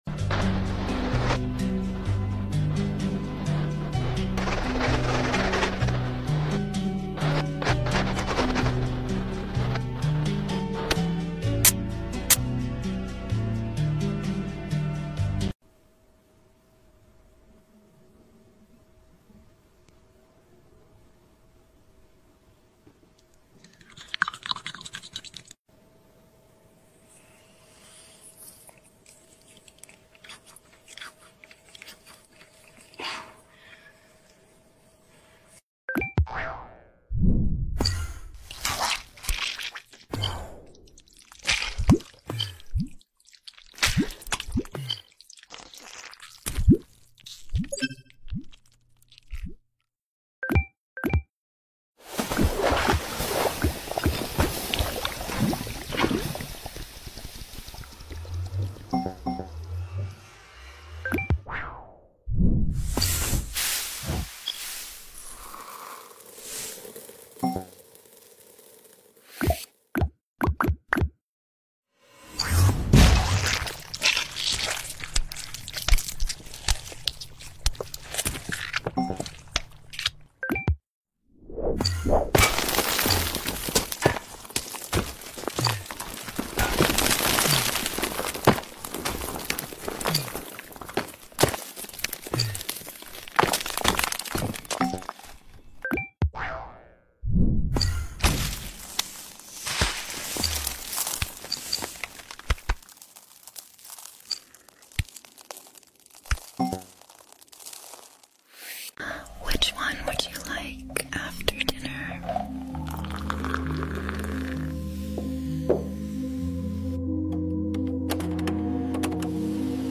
ASMR TikTok sound effects free download
In dieser Zusammenstellung haben wir die besten ASMR-Trigger zusammengestellt, die Ihnen helfen, sich zu entspannen, Stress abzubauen und in einen friedlichen Schlaf abzudriften. Von sanftem Flüstern bis zu beruhigenden Geräuschen, dieses Video hat alles!